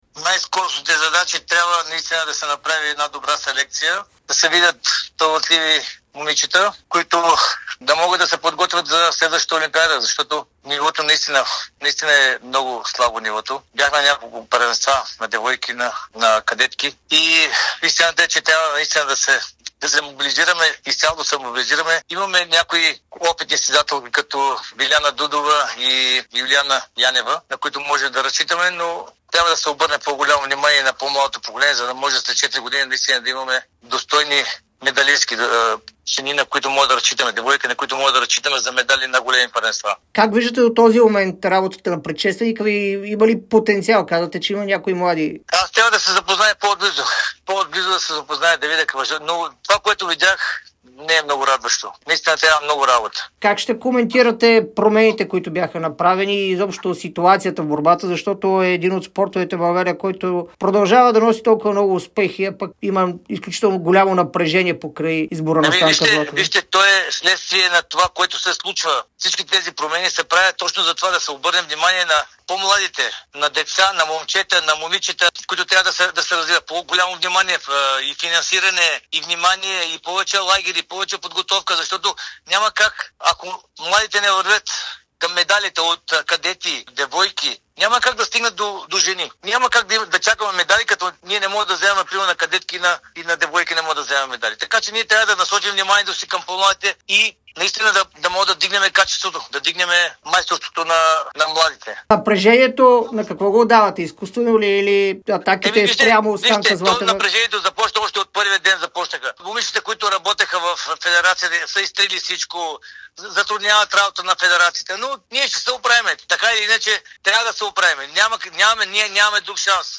говори специално пред Дарик радио и dsport за продължаващото напрежение в българската борба.